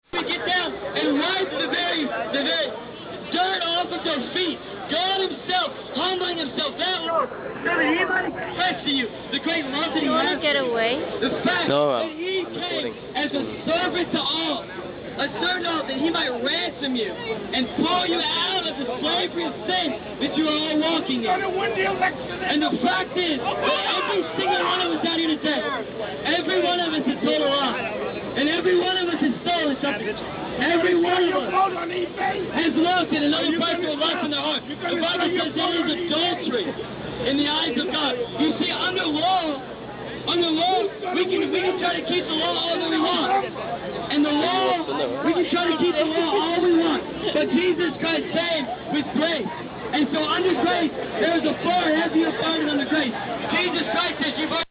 Union Square, la nuit. Un prêcheur très inspiré "enflamme" la place. Certes, les gens à qui il propose son micro en profitent pour passer des petites annonces à caractère sentimental, et d'autres dans le public — comme ce vieux monsieur noir très remonté — exigent de lui qu'il se prononce sur le résultat des élections présidentielles en novembre...
preacher.mp3